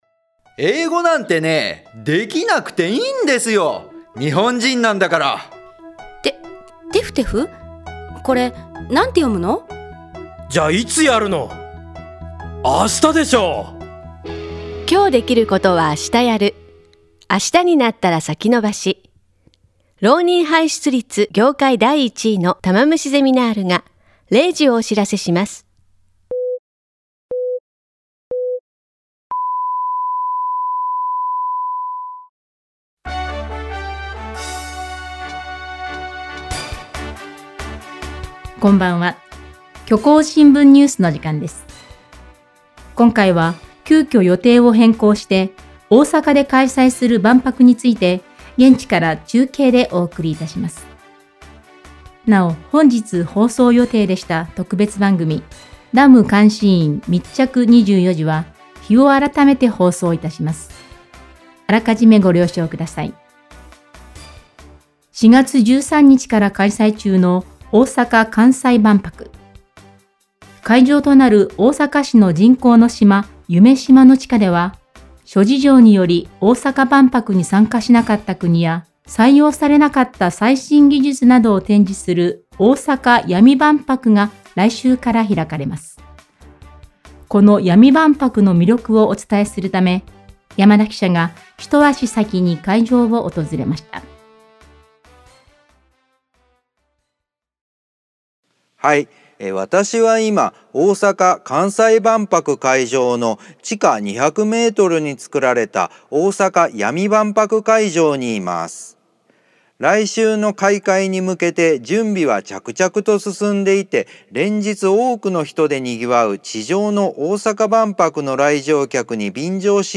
この番組は、他の新聞メディアにはない独自の取材網を持っている虚構新聞社がお届けする音声ニュース番組「虚構新聞ニュース」です。